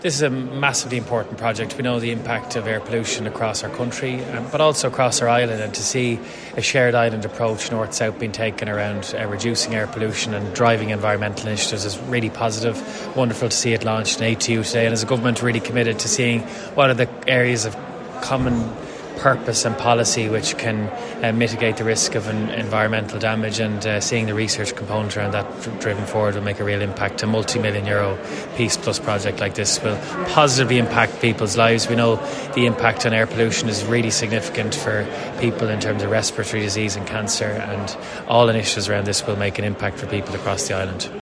Speaking to Highland Radio News at ATU’s Letterkenny campus where today’s launch took place, Public Expenditure Minister Jack Chambers said this is a hugely important programme…………………….
minister-jack-chambers-peaceplus.m4a.mp3